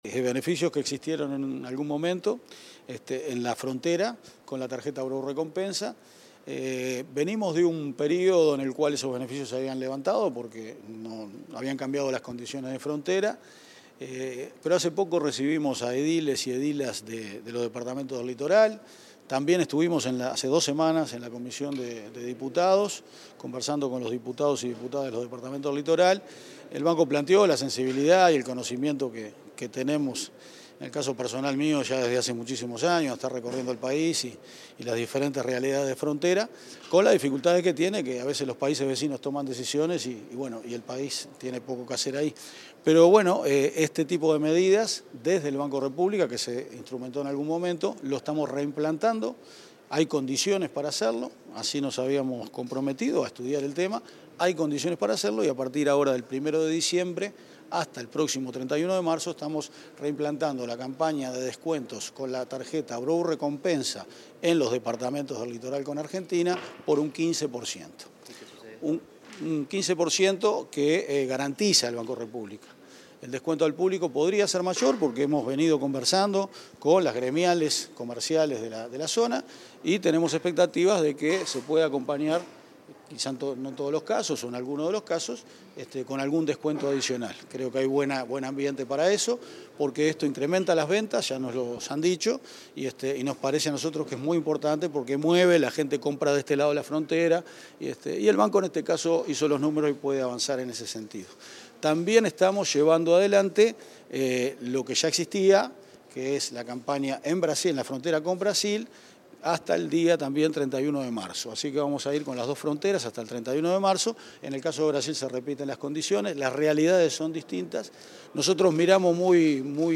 Declaraciones del presidente del BROU, Álvaro García
Declaraciones del presidente del BROU, Álvaro García 28/11/2025 Compartir Facebook X Copiar enlace WhatsApp LinkedIn El presidente del Banco de la República Oriental del Uruguay (BROU), Álvaro García, dialogó con la prensa tras anunciar beneficios especiales con tarjetas de esa entidad en las zonas de frontera.